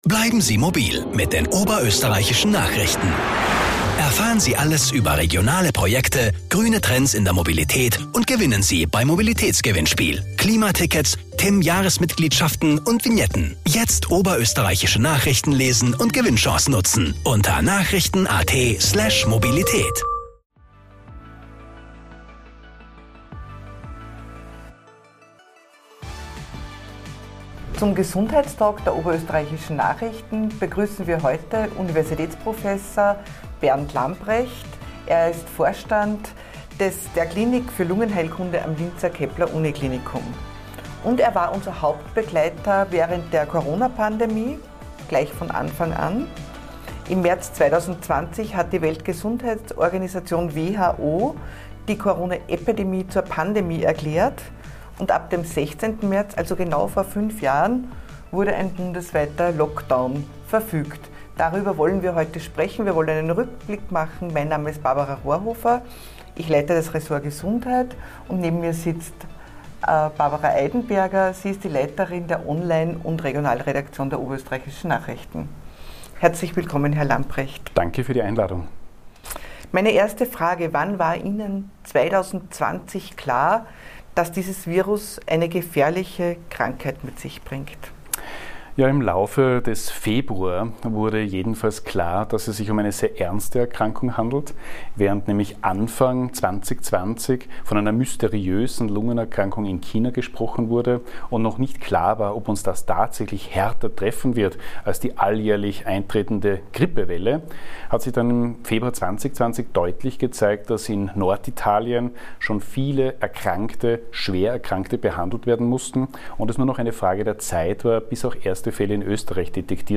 ein Interview und ein Rückblick ...